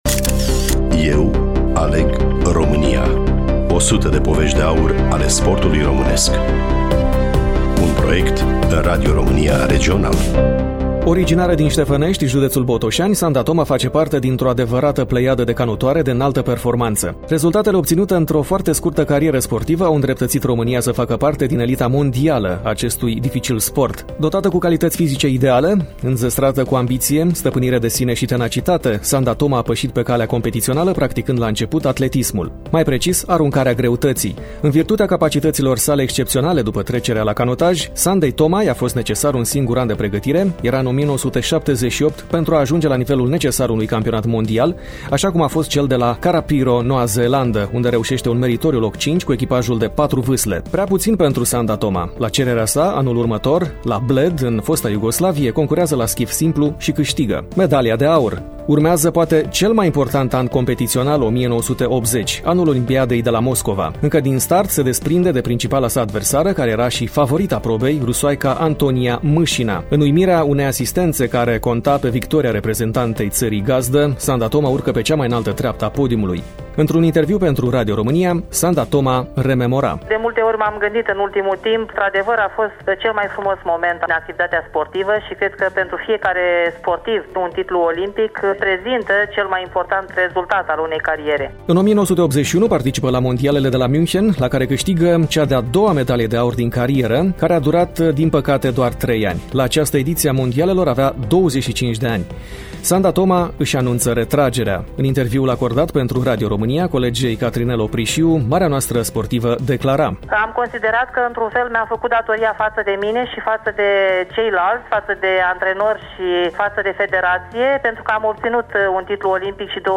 Studioul: Radio Romania Iași